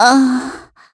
Gremory-Vox_Damage_kr_01.wav